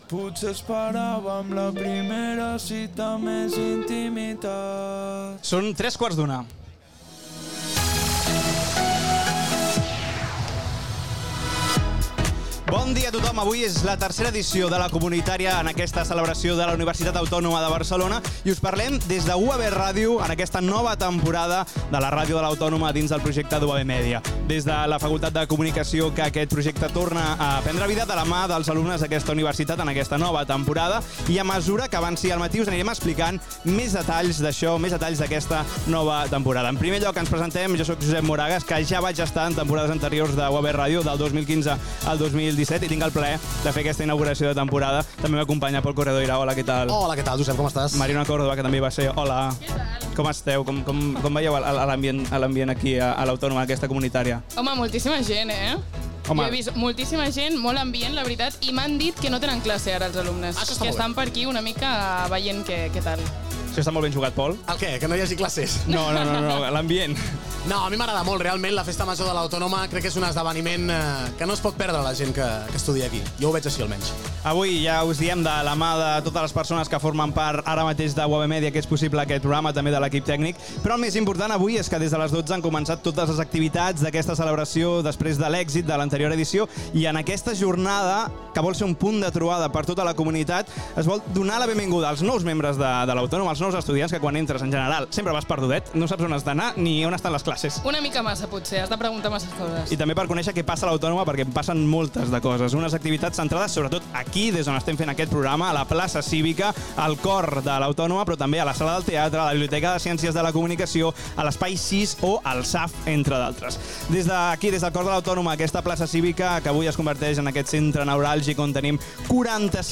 4e44f22abc01782b1c89608ea0925b57a3fc8063.mp3 Títol UAB Ràdio Emissora UAB Ràdio Titularitat Tercer sector Tercer sector Universitària Descripció Hora, presentació del programa especial fet des de la Plaça Cívica del campus de la Universitat Autònoma de Barcelona amb motiu de la celebració de La Comunitària a la UAB.
Info-entreteniment